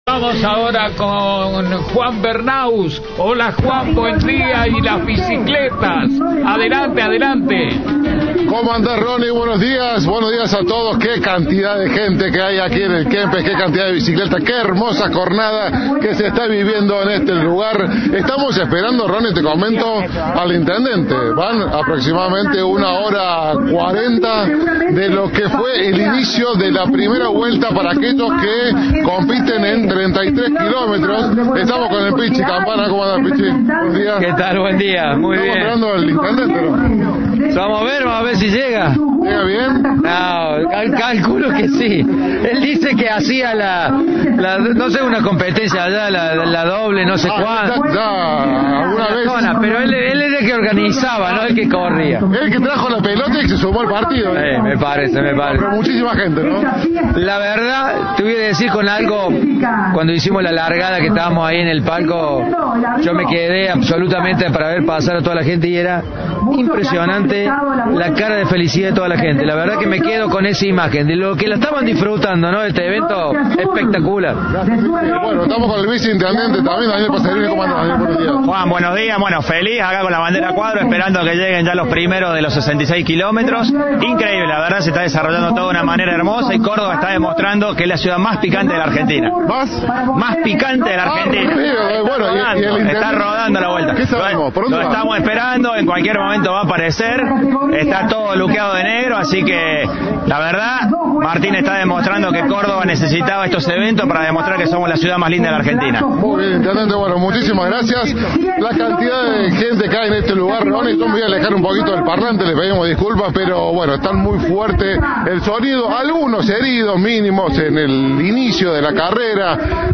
"Estoy feliz con la bandera a cuadros, esperando que llegue el primero. Martín (Llaryora) está demostrando con estos eventos que Córdoba es la ciudad más picante de la Argentina", dijo a Cadena 3 el viceintendente Daniel Passerini.